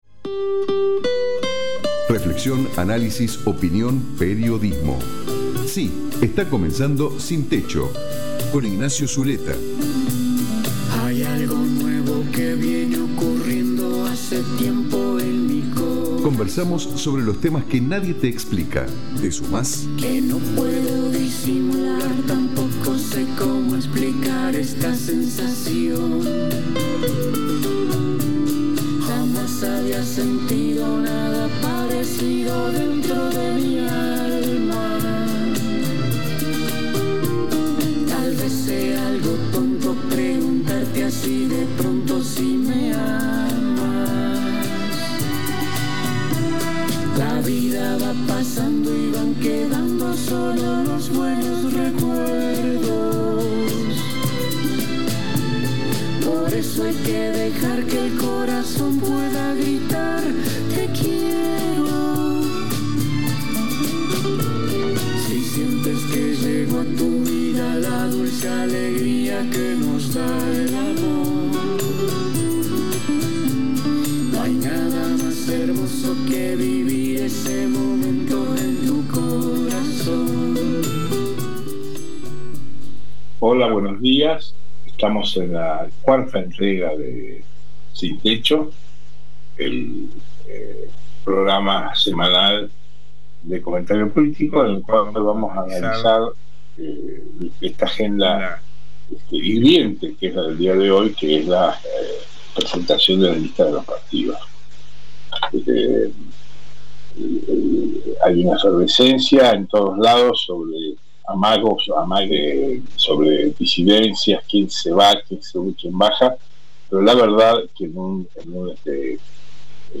Comparto el diálogo en el programa Sin techo que se emite todos los miércoles por la FM Cultura 97.7 de CABA.